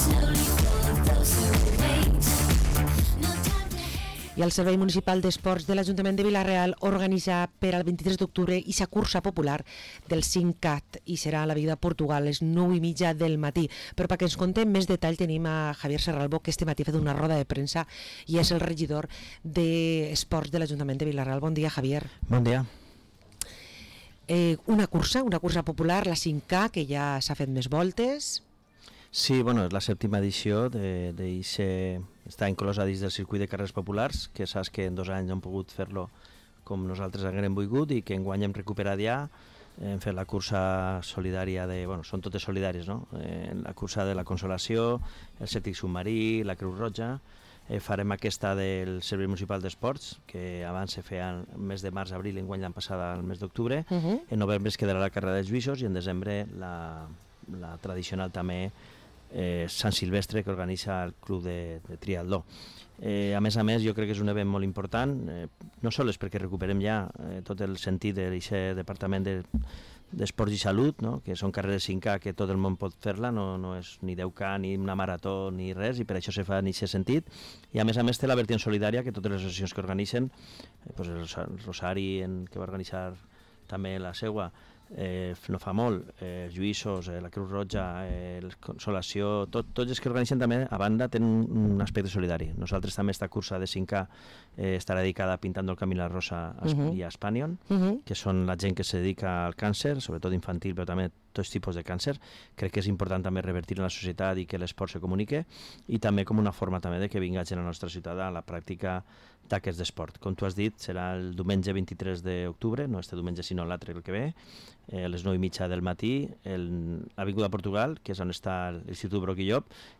El regidor d’Esports de l’Ajuntament de Vila-real, Javier Serralvo, ens presenta la cursa popular que tindrà lloc el 23 d’octubre.